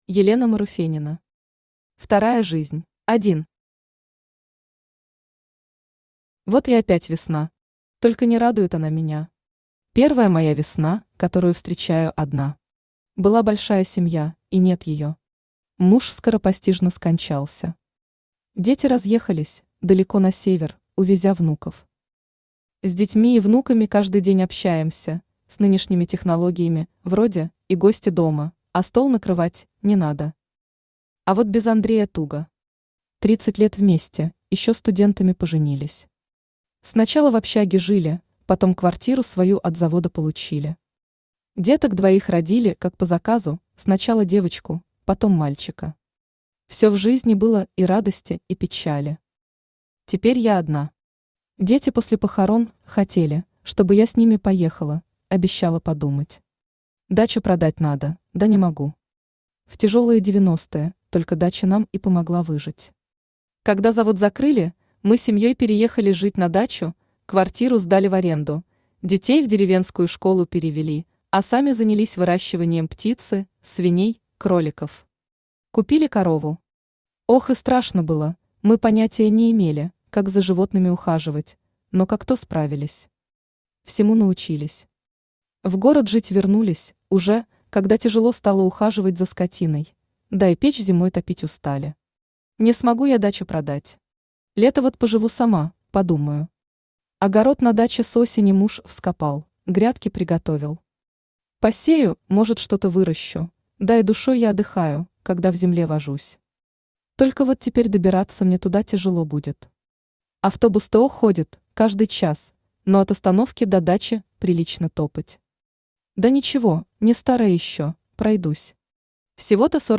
Аудиокнига Вторая жизнь | Библиотека аудиокниг
Aудиокнига Вторая жизнь Автор Елена Николаевна Маруфенина Читает аудиокнигу Авточтец ЛитРес. Прослушать и бесплатно скачать фрагмент аудиокниги